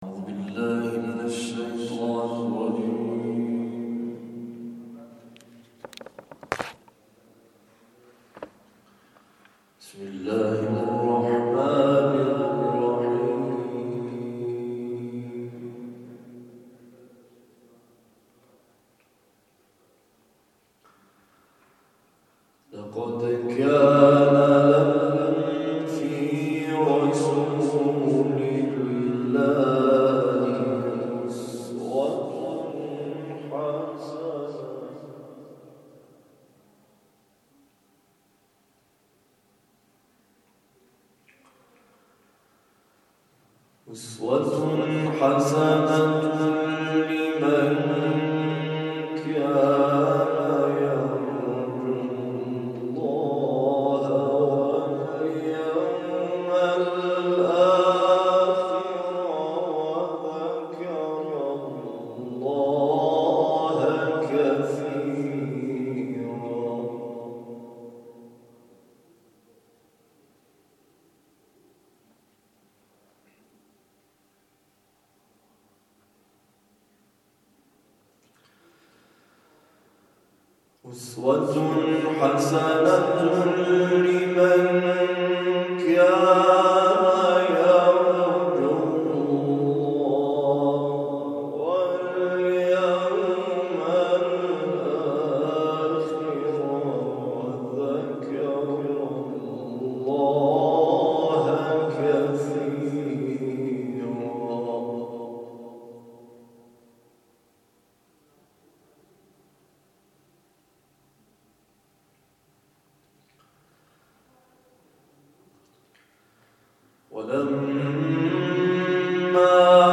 به تلاوت بخشی از آیات سوره احزاب پرداخت.